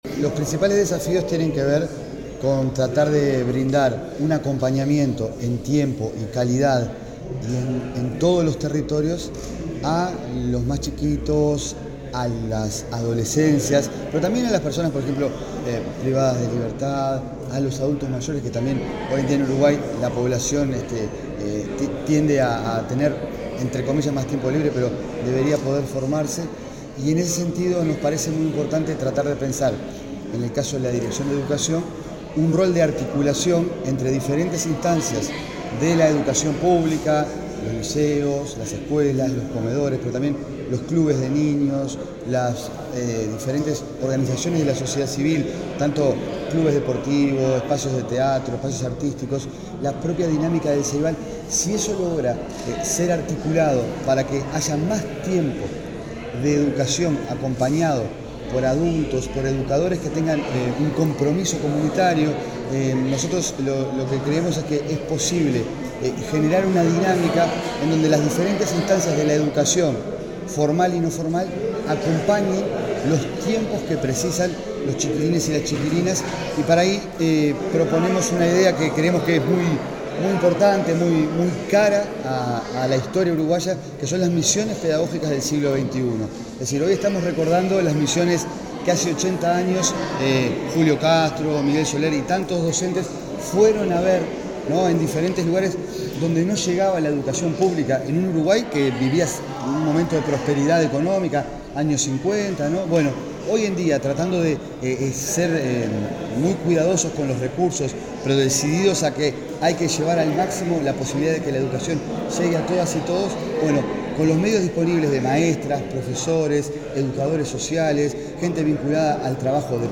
Declaraciones del director de Educación, Gabriel Quirici
Declaraciones del director de Educación, Gabriel Quirici 09/10/2025 Compartir Facebook X Copiar enlace WhatsApp LinkedIn El director de Educación del Ministerio de Educación y Cultura, Gabrel Quirici, realizó declaraciones en el marco del seminario Evidencia para Repensar la Ampliación de Cobertura y Tiempos de Educación y Cuidados.